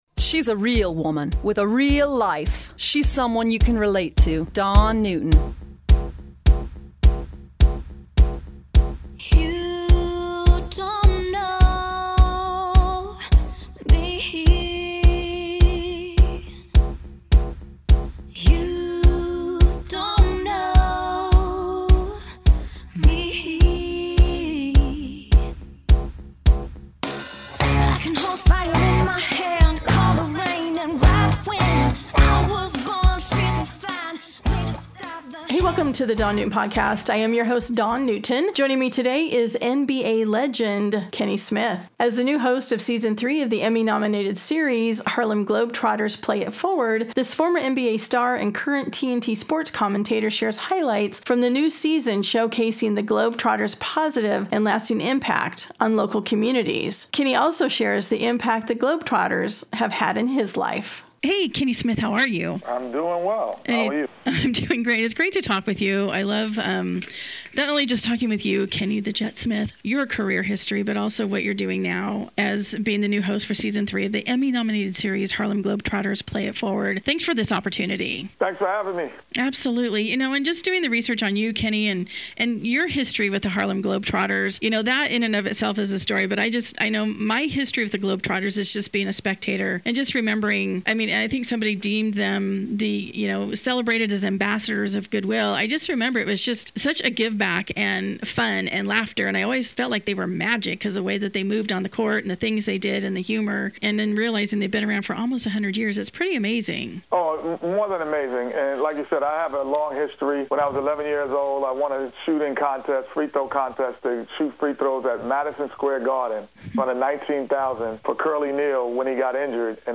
The former NBA star and current TNT sports commentator shares highlights from the new season showcasing the Globetrotters positive and lasting impact on local communities, as well as, the impact the Globetrotters have had in his life.